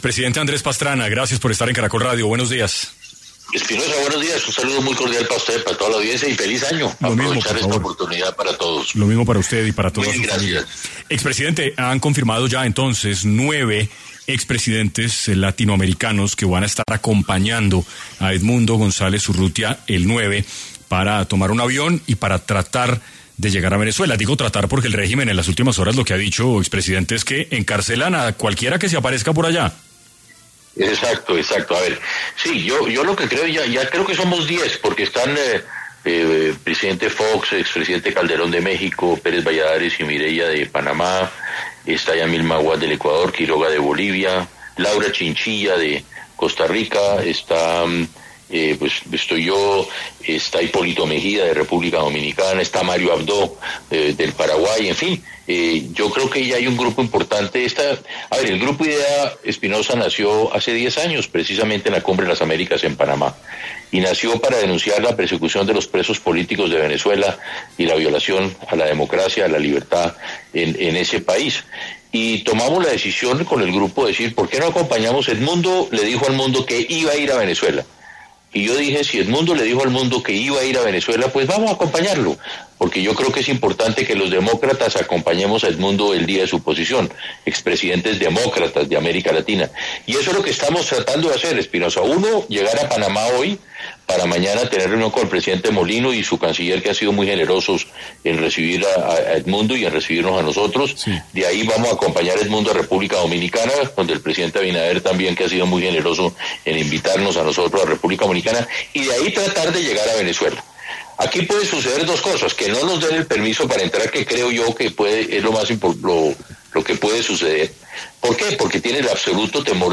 En Caracol Radio estuvo Andrés Pastrana, expresidente de Colombia, conversando sobre su apoyo a Edmundo González